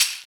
• Smooth Shaker Hi Hat C# Key 05.wav
Royality free shaker percussion tuned to the C# note. Loudest frequency: 5825Hz
smooth-shaker-hi-hat-c-sharp-key-05-x1E.wav